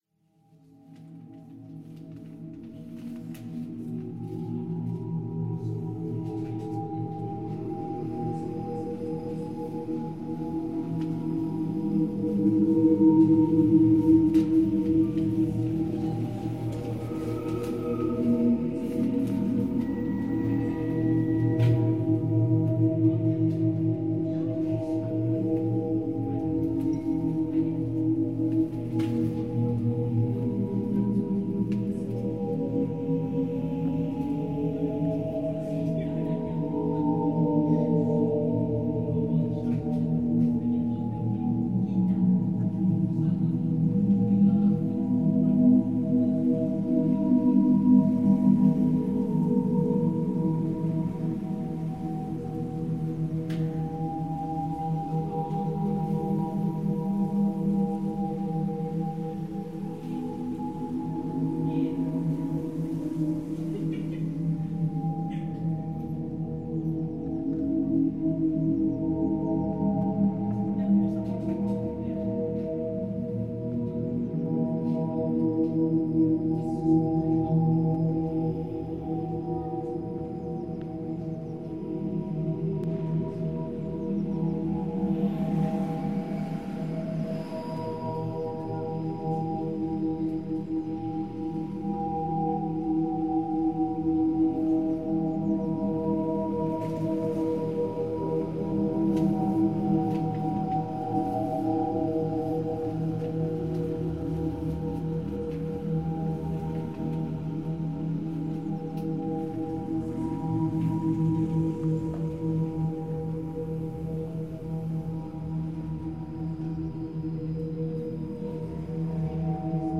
reimagining of his own sound from Antwerp's public park